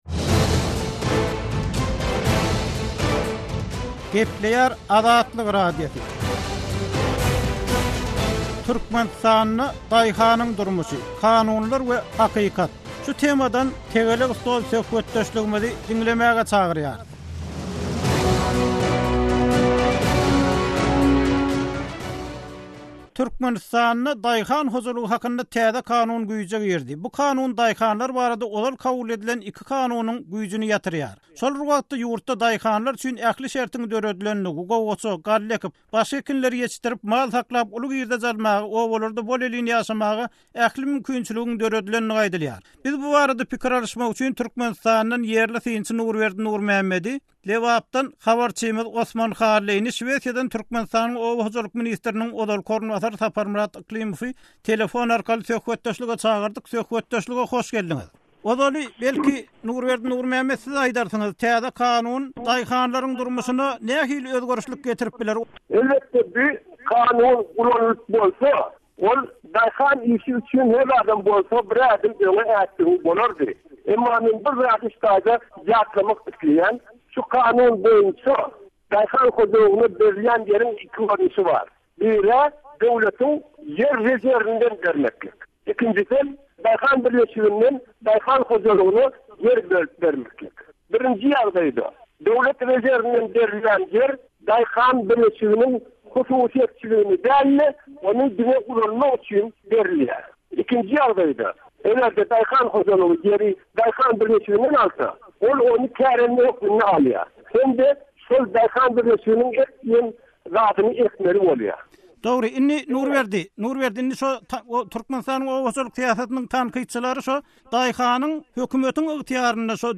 Azatlyk Radiosy nobatdaky «Tegelek stol» söhbetdeşligini türkmen daýhanlarynyň ýüzbe-ýüz bolýan kynçylyklaryna, bu kynçylyklaryň arkasynda ýatan zatlara we mümkin bolan çözgütlerine bagyşlady.